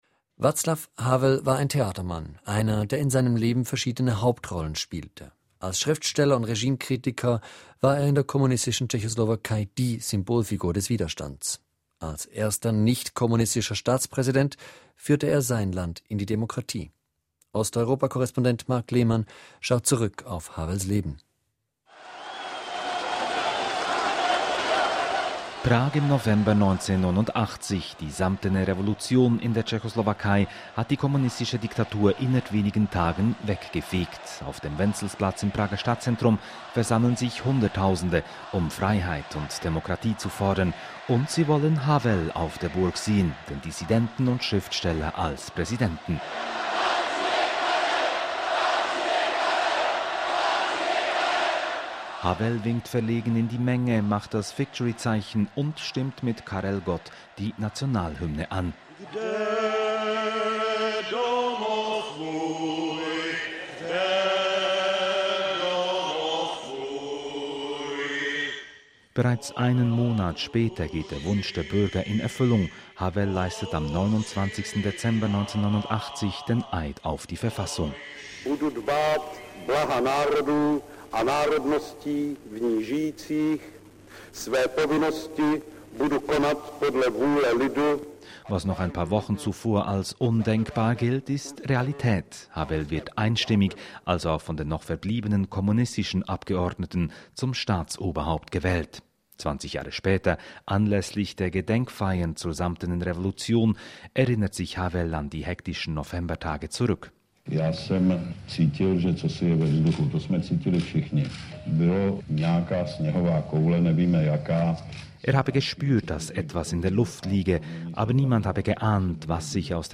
Als erster nicht-kommunistischer Staatspräsident führte er sein Land in die Demokratie. Ein Nachruf.